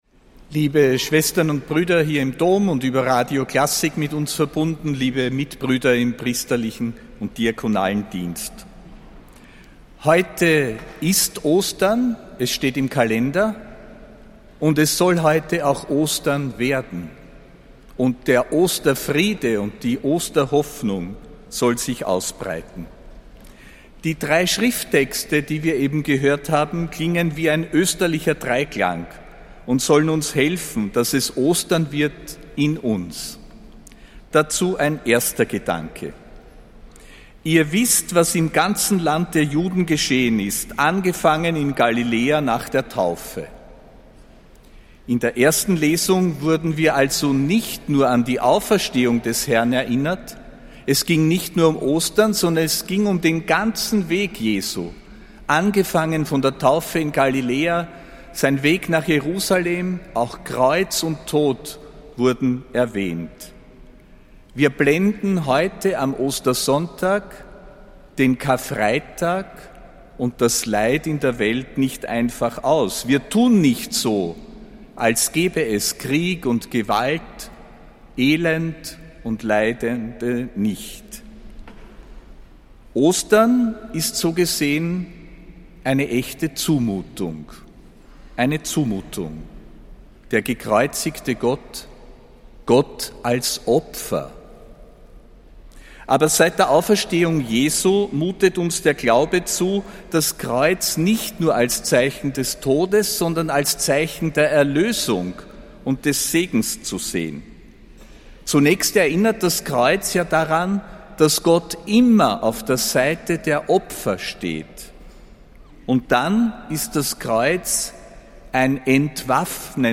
Predigt von Erzbischof Josef Grünwidl zum Ostersonntag, am 5. April 2026.